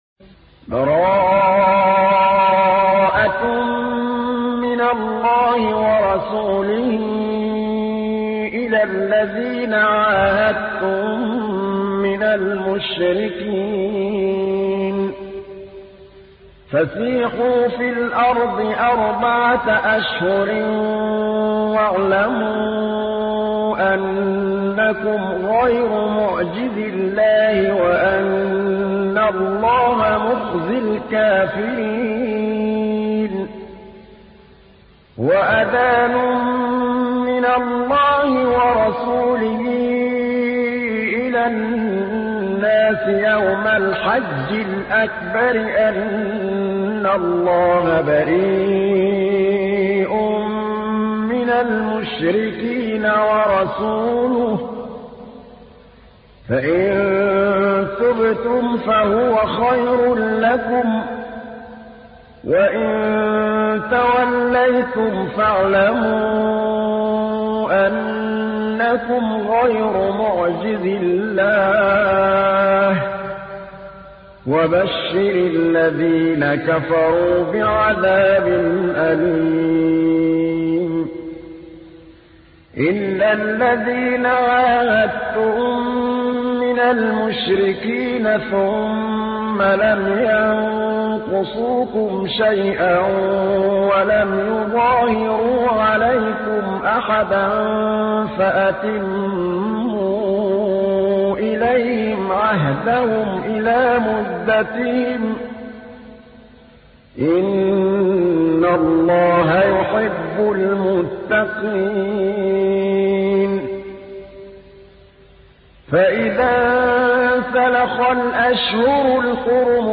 تحميل : 9. سورة التوبة / القارئ محمود الطبلاوي / القرآن الكريم / موقع يا حسين